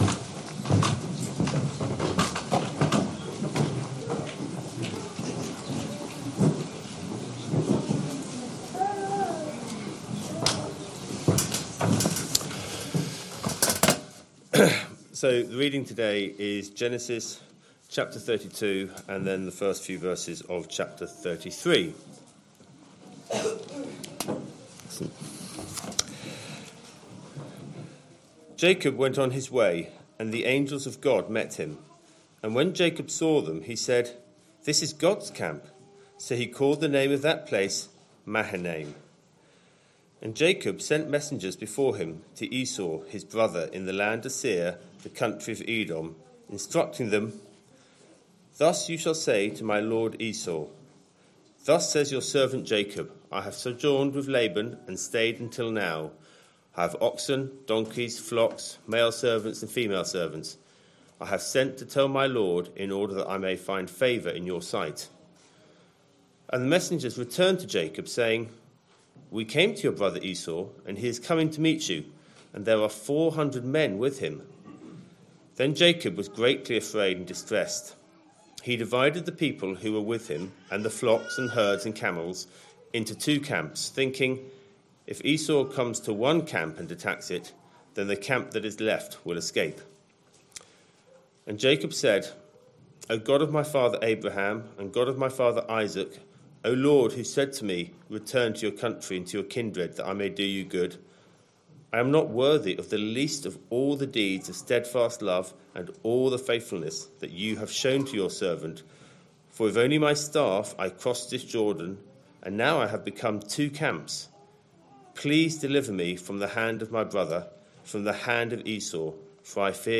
Christ Church Sermon Archive
Sunday AM Service Sunday 11th January 2026 Speaker